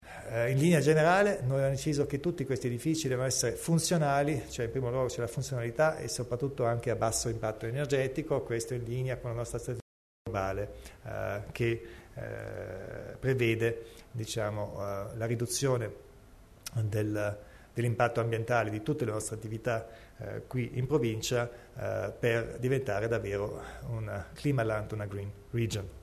Il Presidente Kompatscher elenca le priorità nel settore edilizio